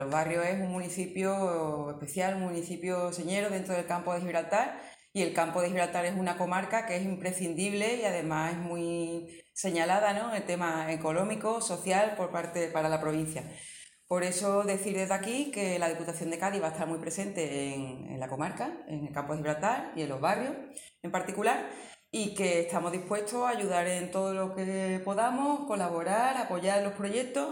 Corte de Almudena Martínez